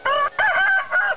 The cock of this breed does not generally exceed five or six pounds and has a unique abbreviated
crow.